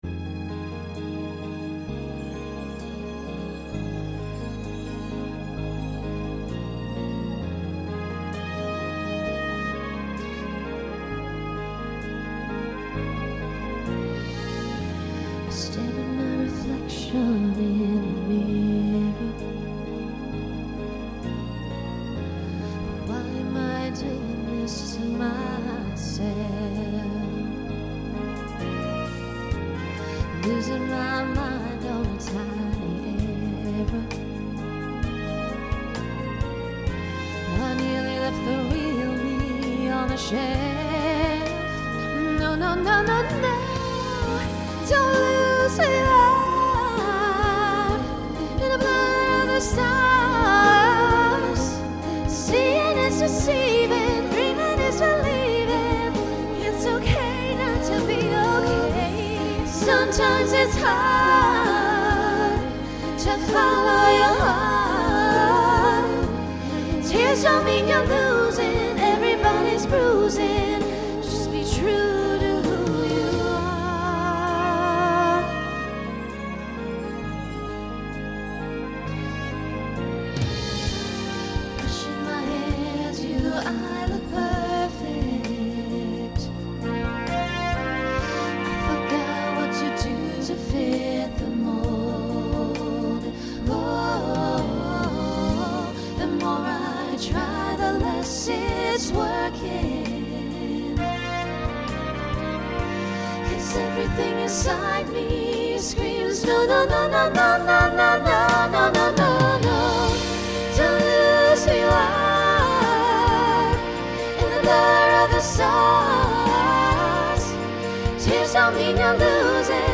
New SATB voicing for 2023.